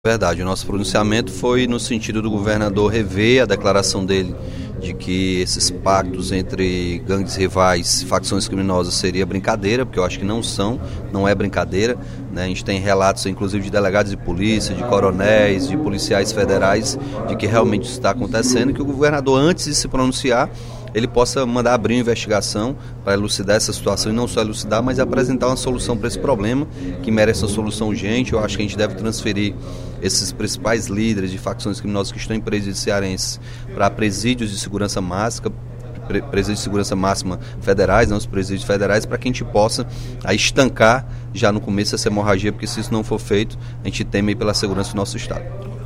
O deputado Capitão Wagner pediu, durante o primeiro expediente da sessão plenária desta sexta-feira (05/02), ao governador Camilo Santana, a abertura de investigações sobre um possível acordo entre facções criminosas. De acordo com o parlamentar, a redução dos homicídios do Estado pode estar relacionada aos entendimentos entre os grupos Comando Vermelho (CV) e Primeiro Comando da Capital (PCC), surgindo dessa união a organização Guardiões do Estado.